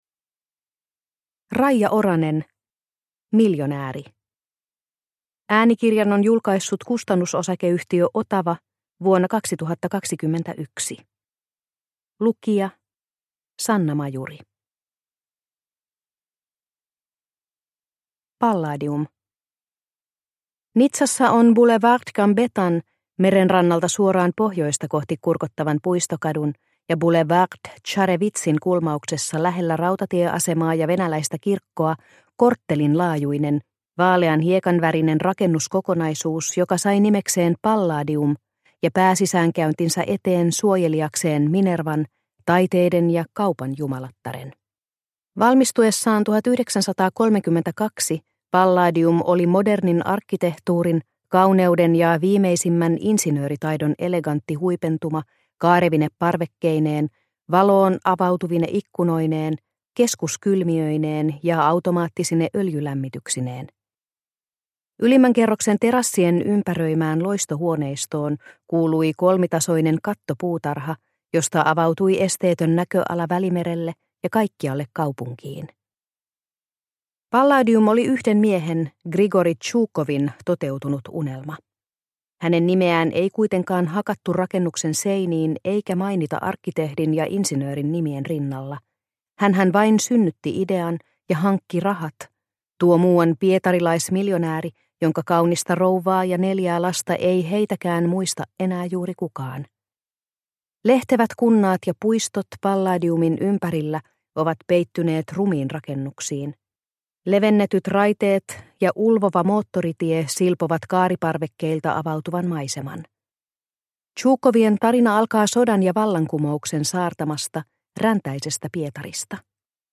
Miljonääri – Ljudbok – Laddas ner